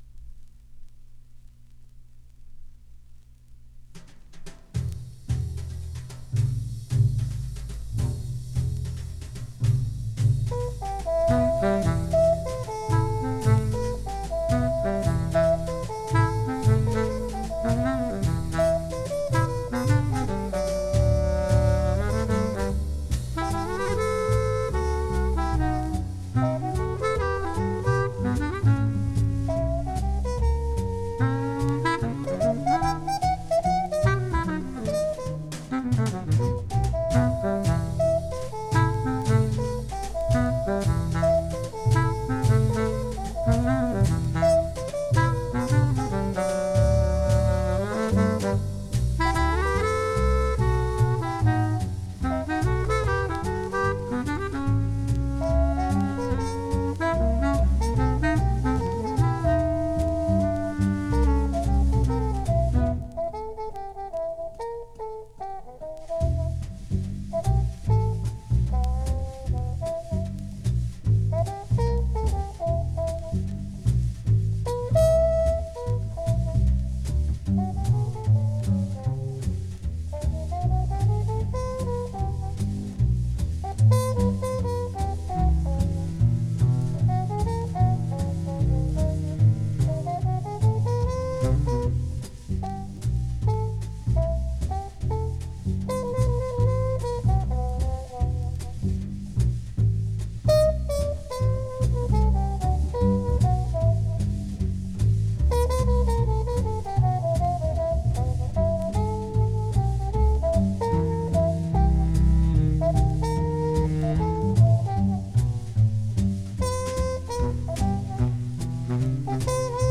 Recorded:  15 January, 1959 in New York City